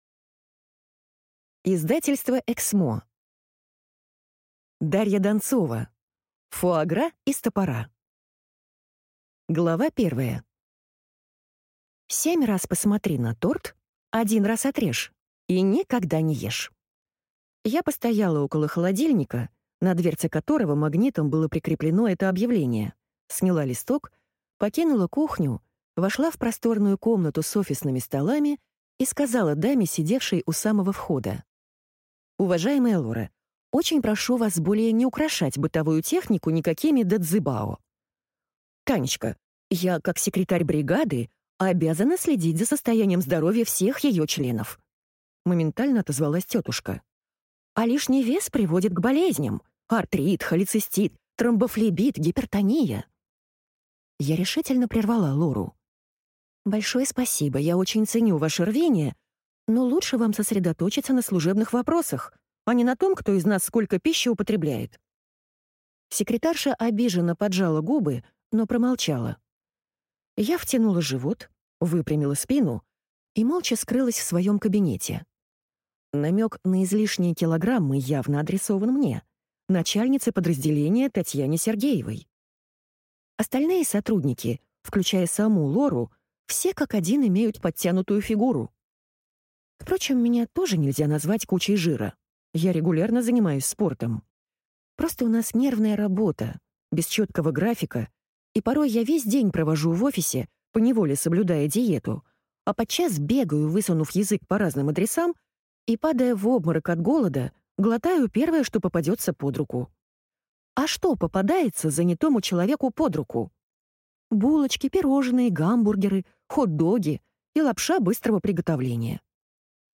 Аудиокнига Фуа-гра из топора | Библиотека аудиокниг